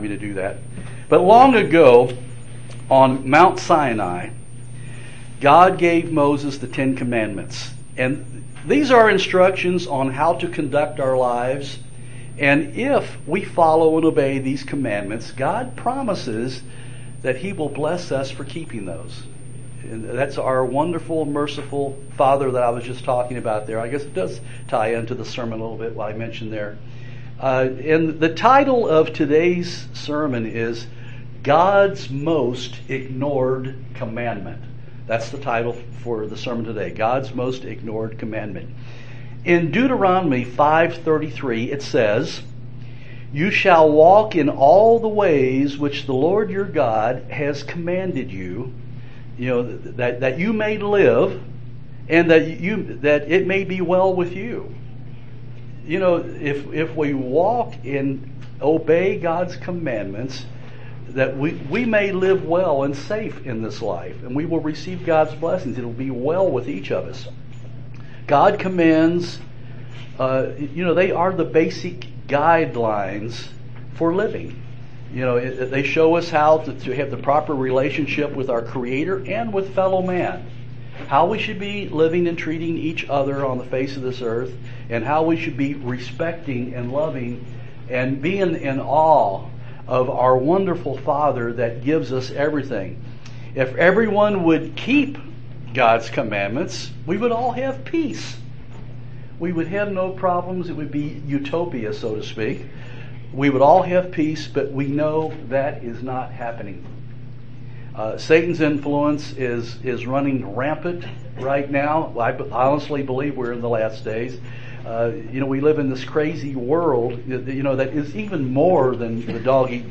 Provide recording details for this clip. Given in Bowling Green, KY